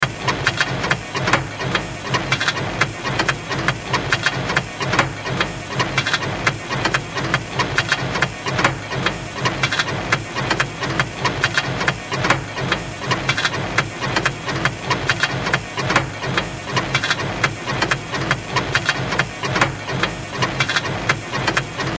Misc / Sound Effects 28 Sep, 2025 Printing Press Mechanical Loop Sound Effect Read more & Download...
Printing-press-mechanical-loop-sound-effect.mp3